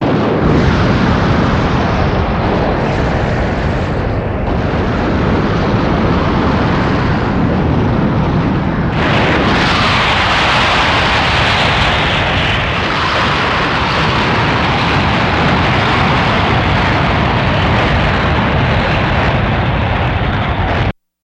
Afterburner Jetwash High Velocity